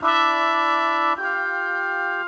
GS_MuteHorn_105-EG.wav